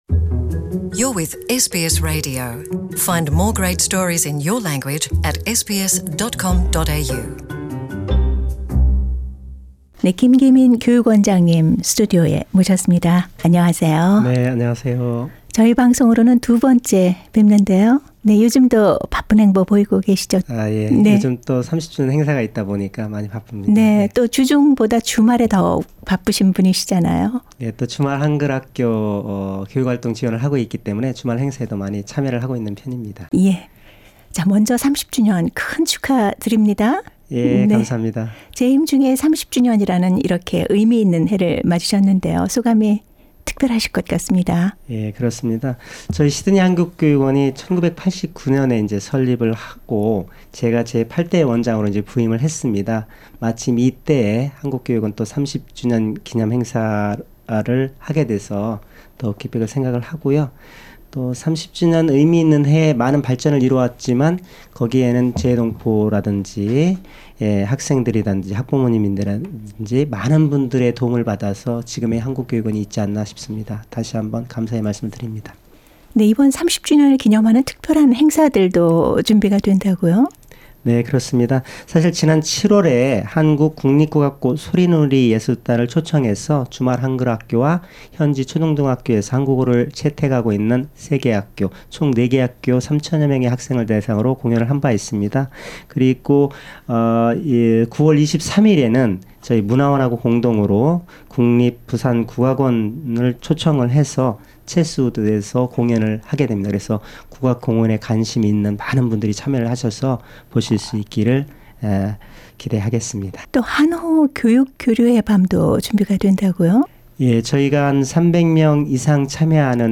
[특별대담] 주 시드니 한국교육원, 호주 내 한국어 교육 개척 30년